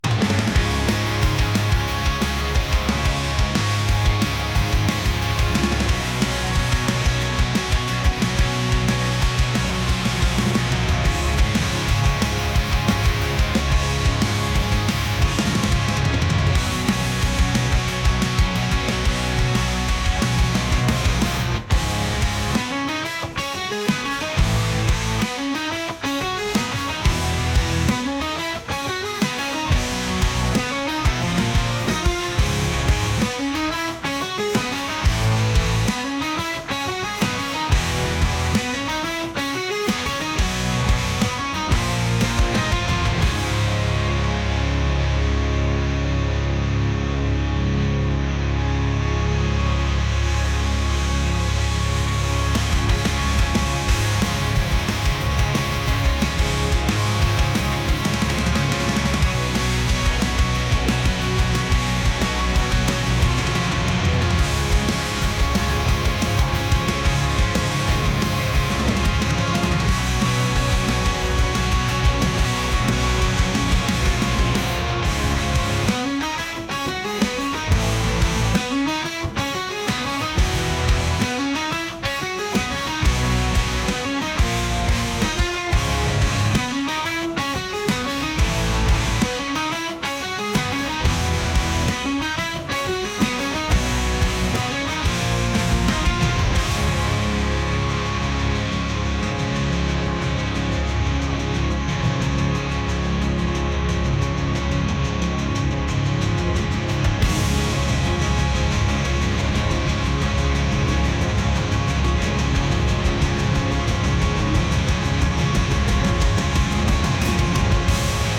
alternative | rock | grunge